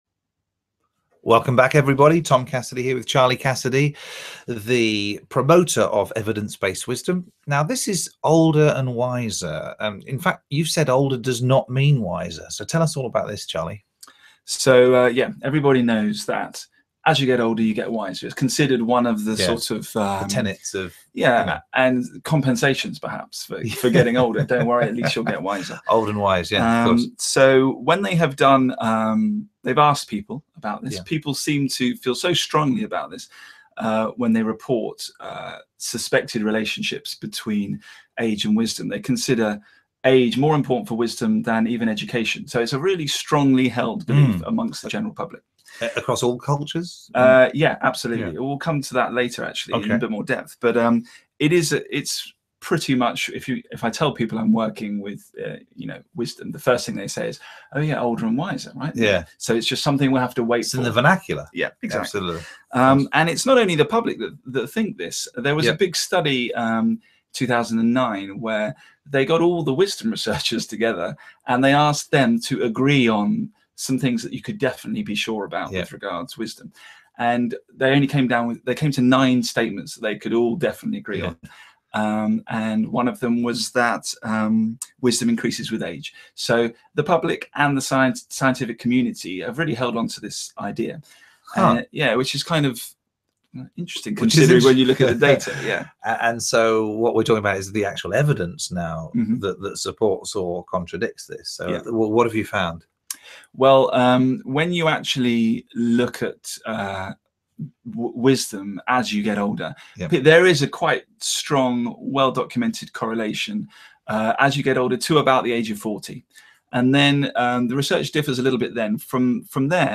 The Evidence-based Wisdom Screencast Series is a collection of short conversations discussing 10 of the major ideas and themes emerging from the field of Wisdom Research.